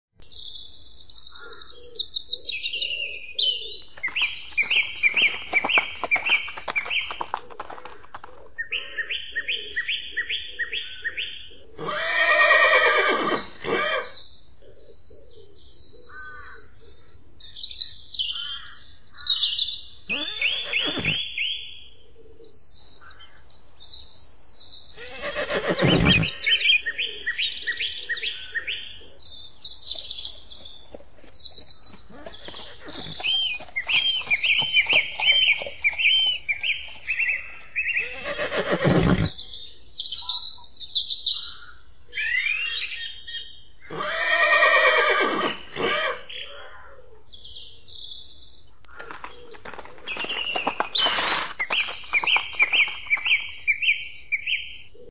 HQ Sound Module - Nature Series -- Country Stable